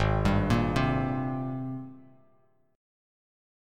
G#M9 chord